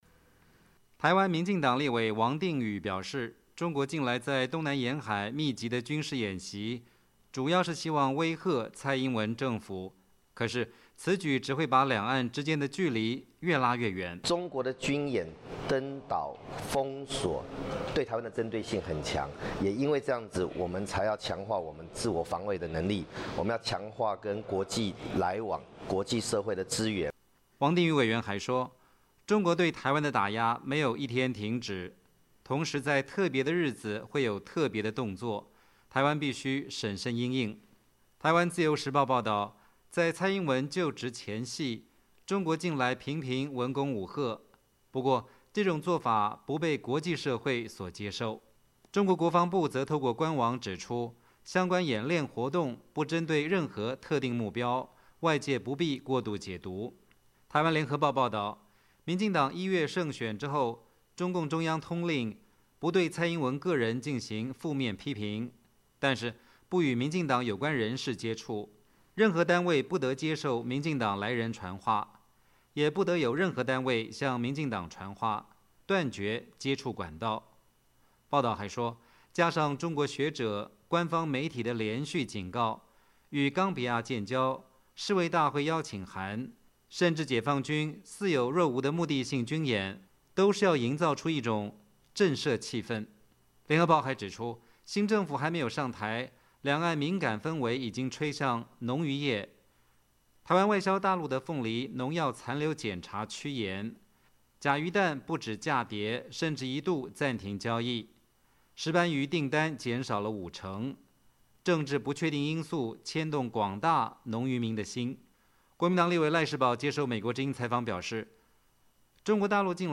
国民党立委赖士葆接受美国之音采访表示，中国大陆近来对台湾的文攻武吓，确实只会让台湾的民心越走越远。